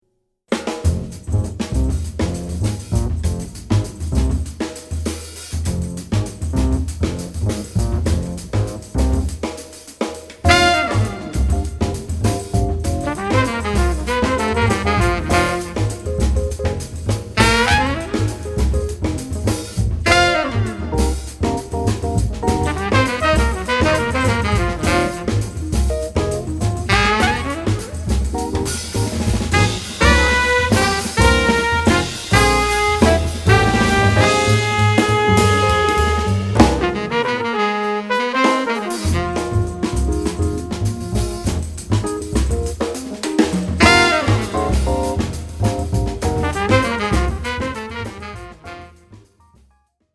Genre: Jazz.
tenor saxophone
trumpet
an odd, monaural sound